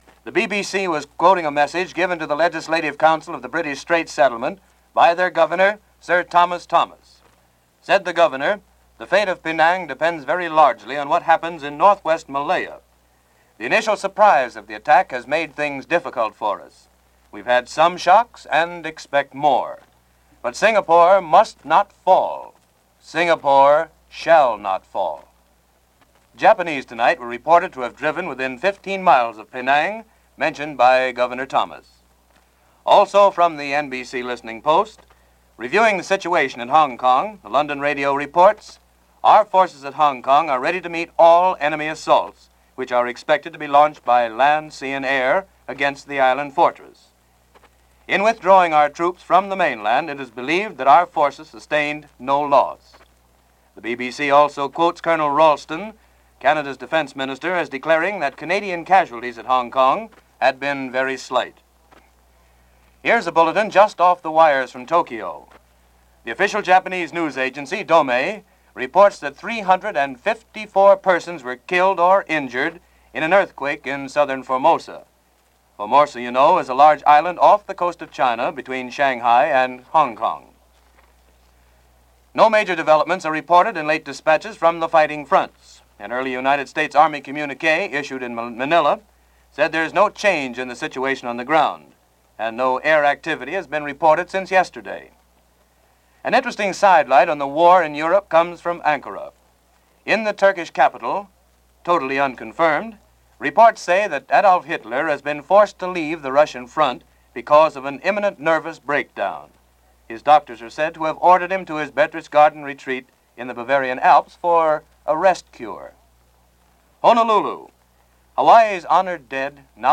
December 17, 1941 – NBC News Bulletins and Reports – Gordon Skene Sound Collection –